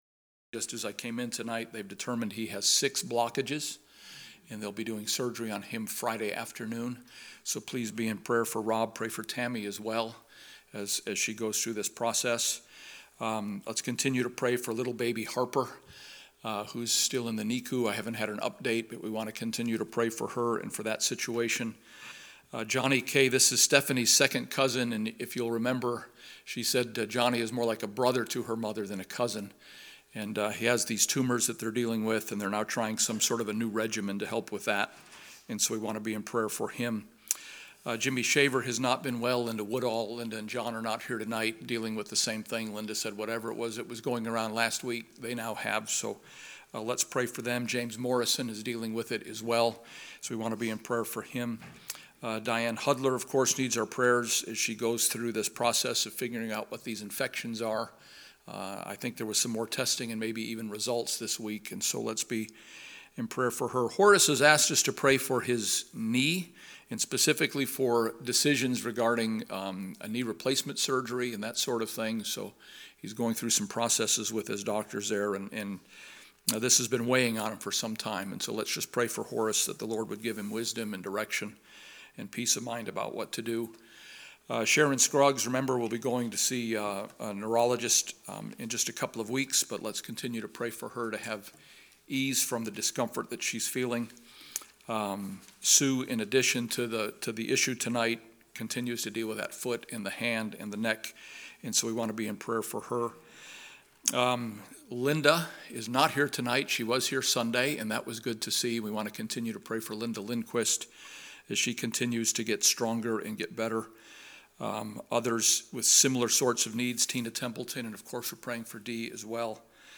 Romans Bible Study 10 – Bible Baptist Church